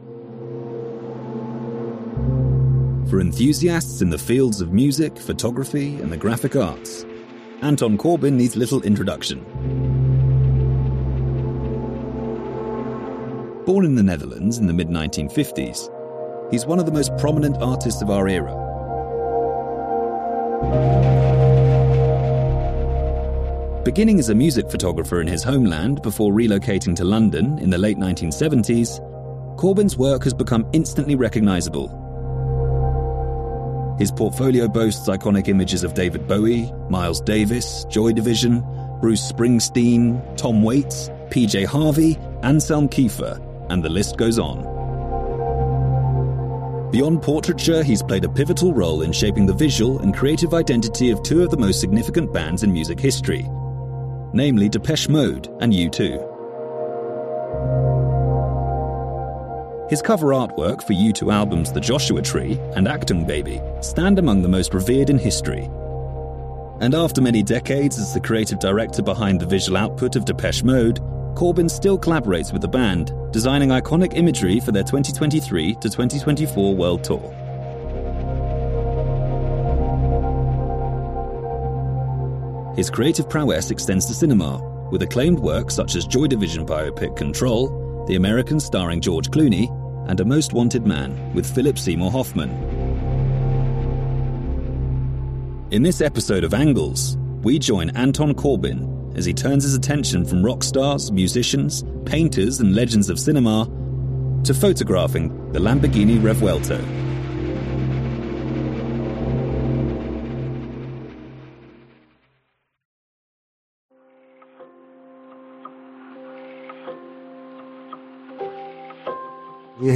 Mi voz cálida y tranquilizadora puede darle vida a cualquier guion, proyecto o resumen.